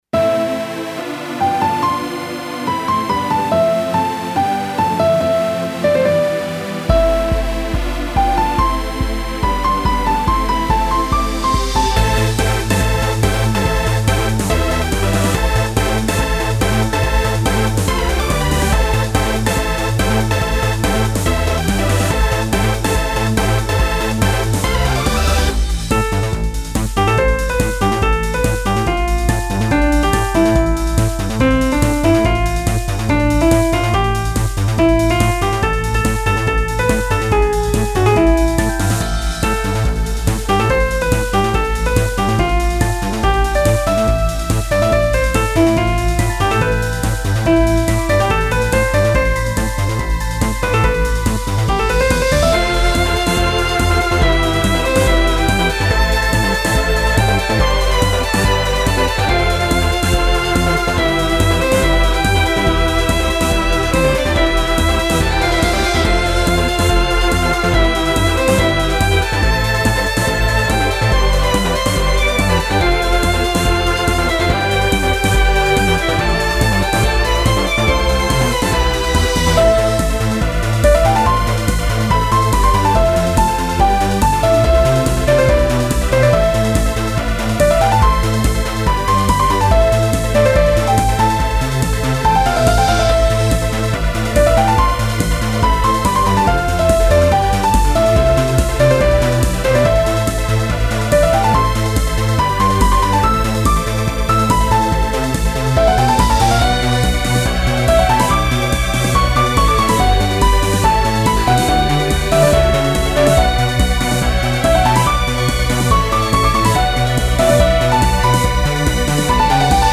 フリーBGM イベントシーン 熱い・高揚
フェードアウト版のmp3を、こちらのページにて無料で配布しています。